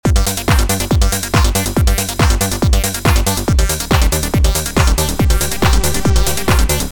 Art Type: Music